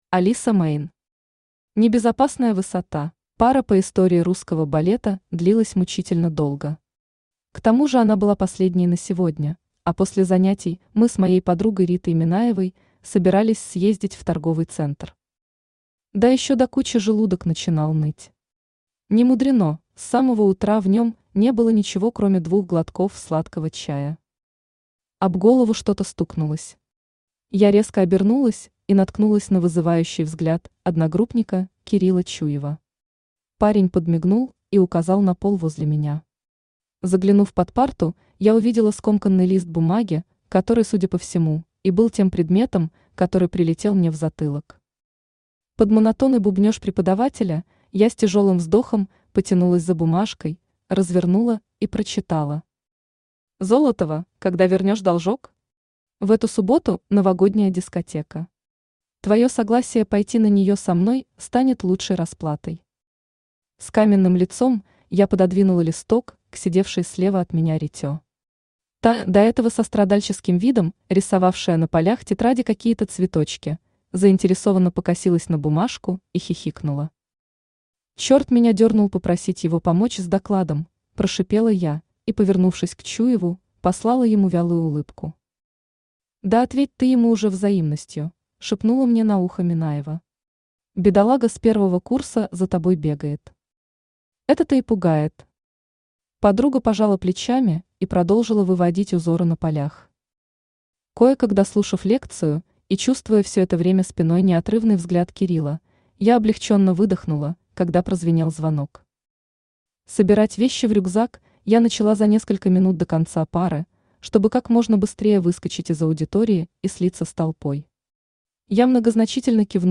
Аудиокнига Небезопасная высота | Библиотека аудиокниг
Aудиокнига Небезопасная высота Автор Алиса Мейн Читает аудиокнигу Авточтец ЛитРес.